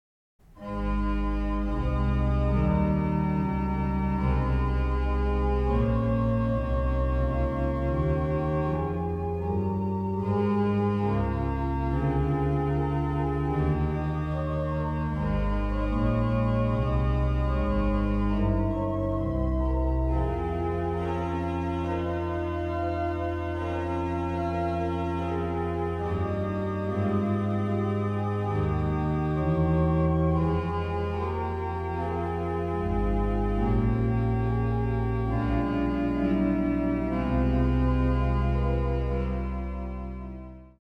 Koraalvoorspel